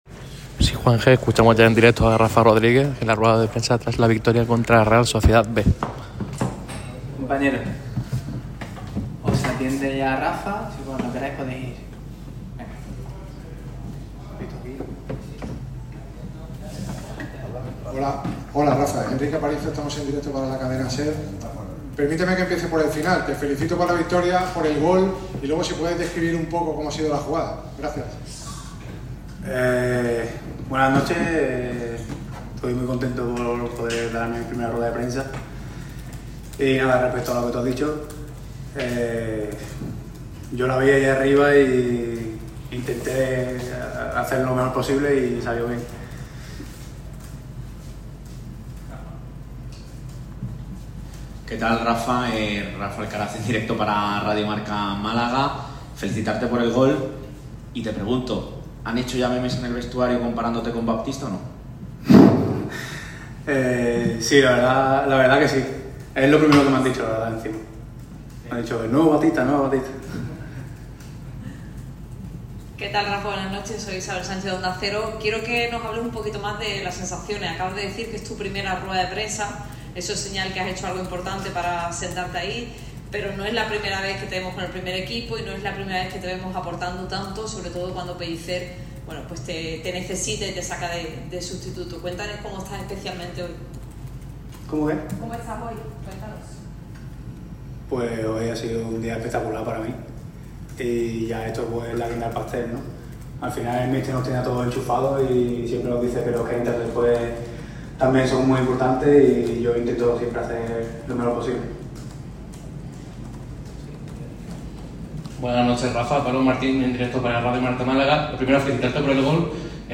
Feliz y nervioso al mismo tiempo, hablaba sobre su chilena.